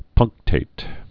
(pŭngktāt) also punc·tat·ed (-tātĭd)